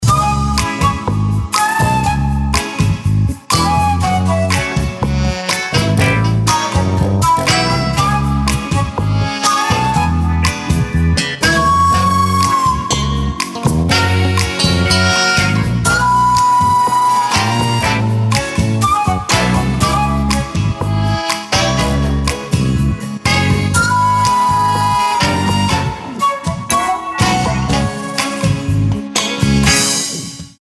• Качество: 128, Stereo
громкие
красивые
без слов
инструментальные
вестерн
ковбойская тема